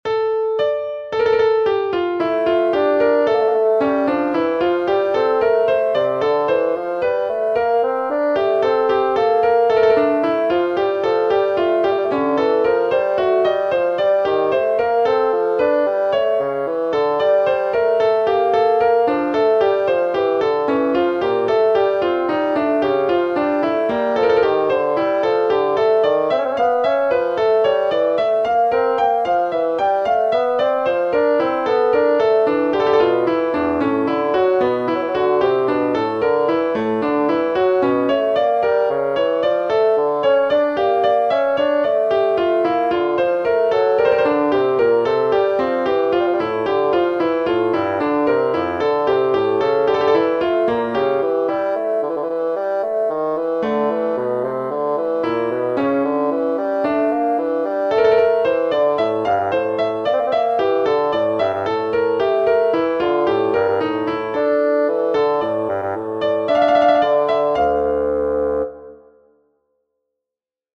Hi! this is invention for two instruments. The first voice melody is nice, but second voice could be better. It sounds now confusing.